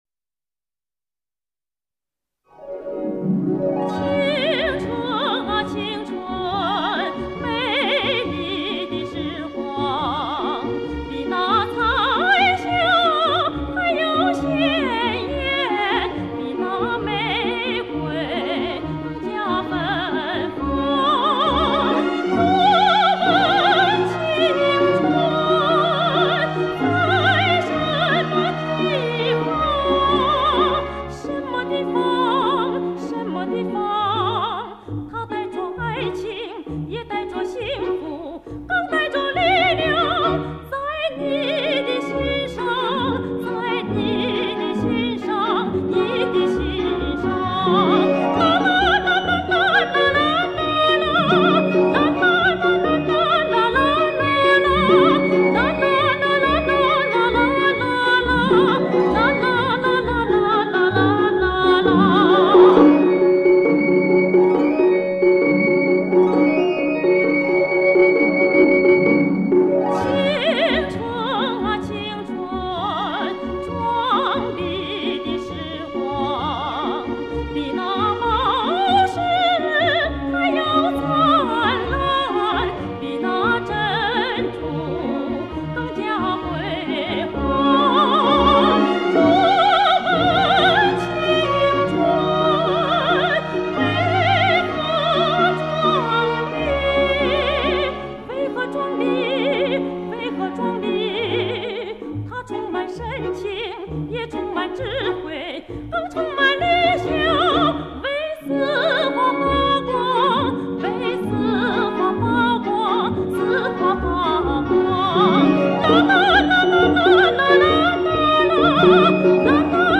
珍贵的历史录音 重温老歌经典